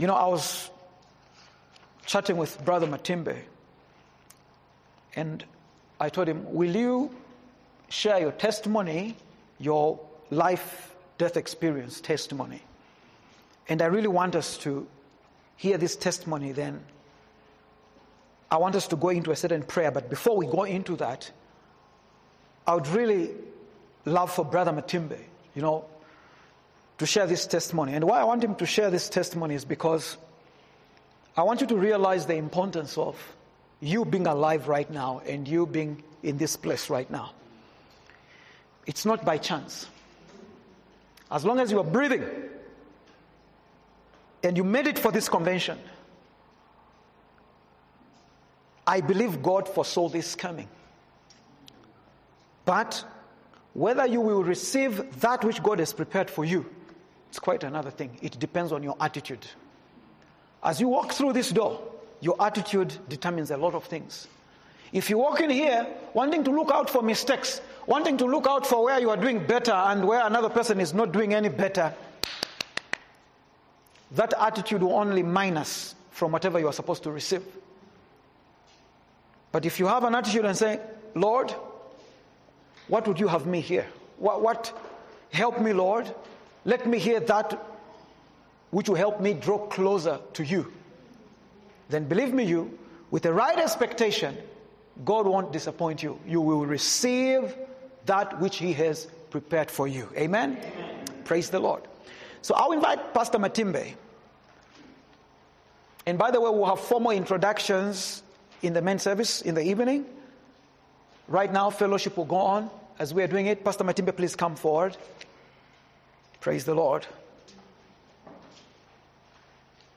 Testimony of Coming back to Life
Daily Devotion Convention 2024